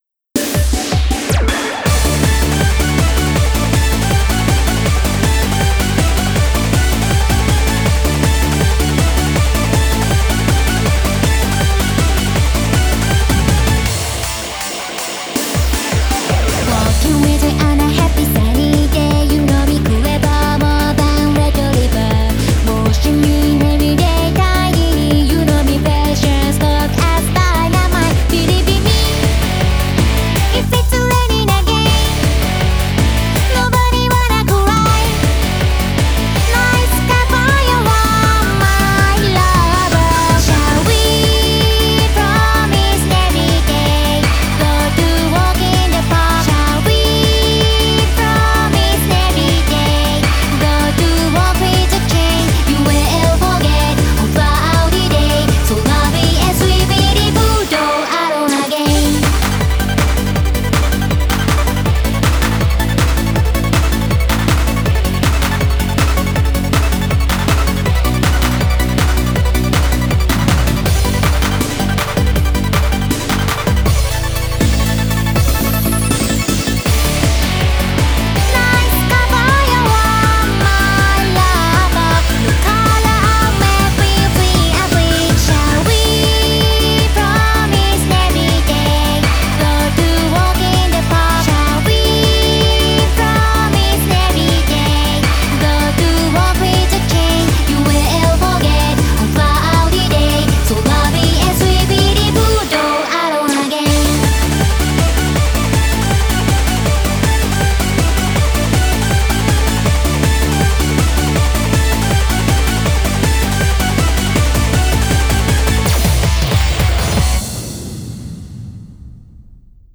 BPM160
Audio QualityPerfect (High Quality)
It has a fun Eurobeat theme song and that's about it.